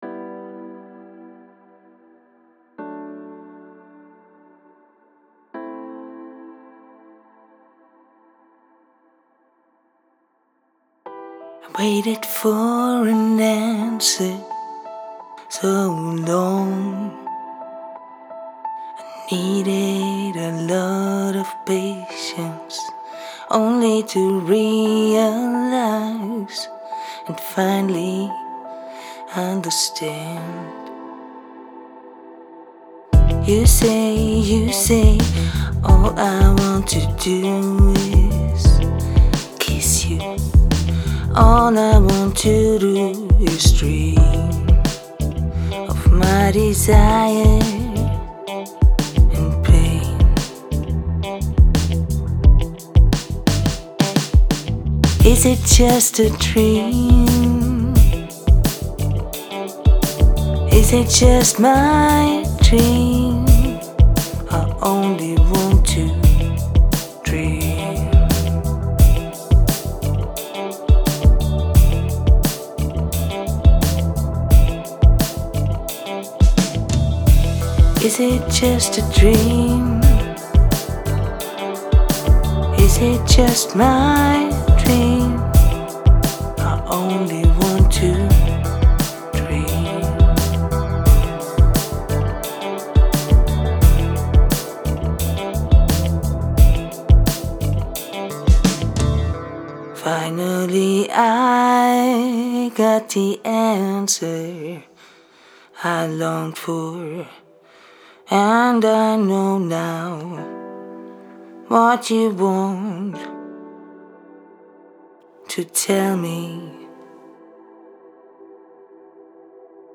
Ist ja aus der Dose.
Bass und 2 Gitarren, das sind Loops, was ich normalerweise nicht verwende.